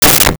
Balloon Pop
Balloon Pop.wav